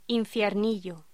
Locución: Infiernillo